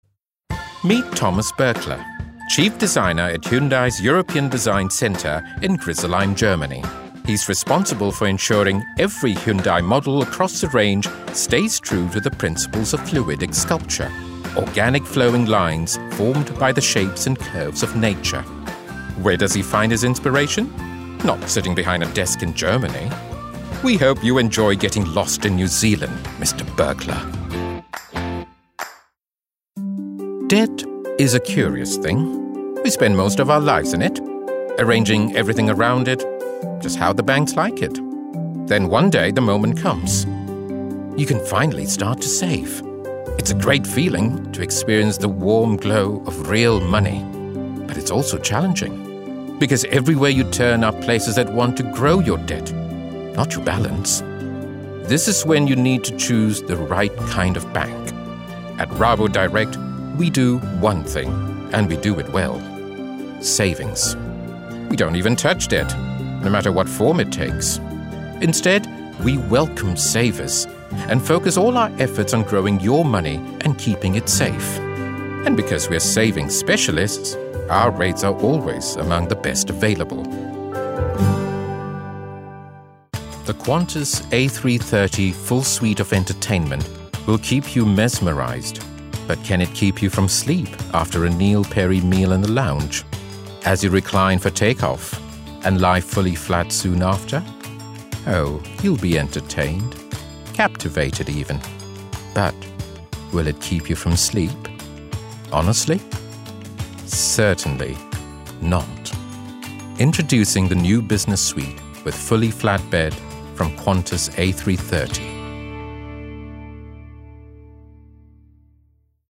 Demo
Adult, Mature Adult
british rp | natural
indian | natural
international english